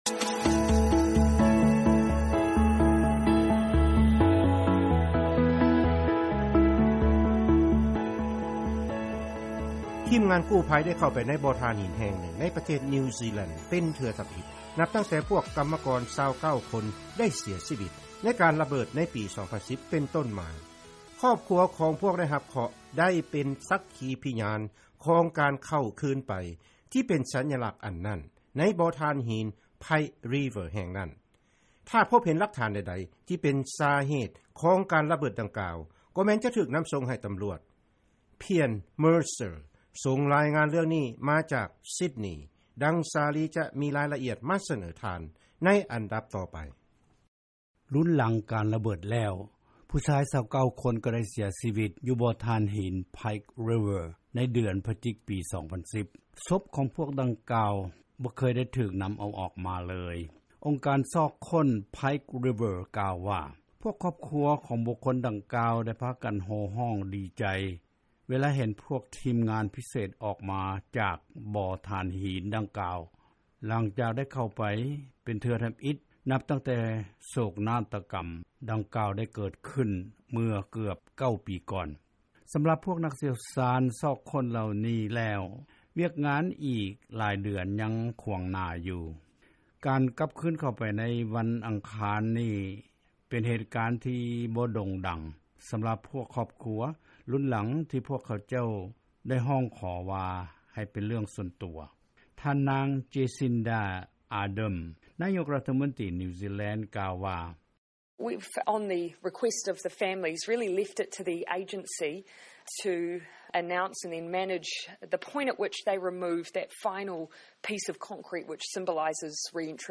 ເຊີນຟັງລາຍງານກ່ຽວກັບປະເທດນິວຊີແລນ